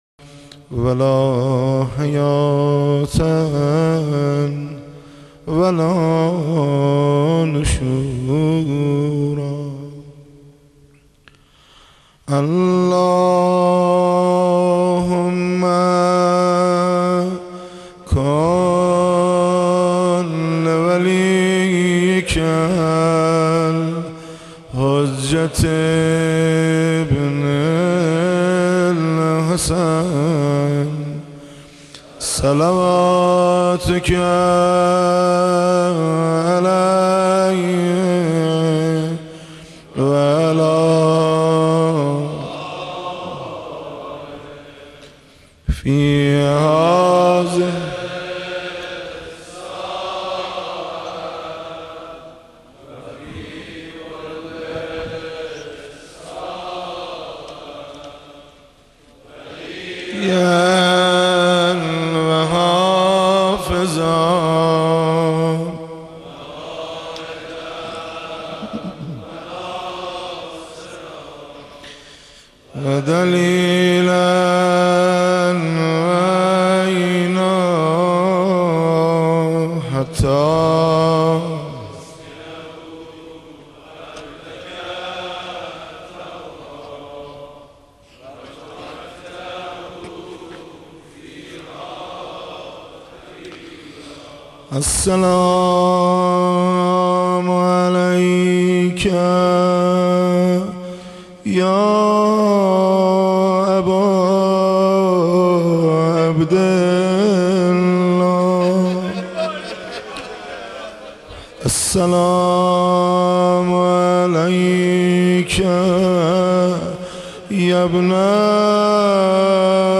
شب دوم محرم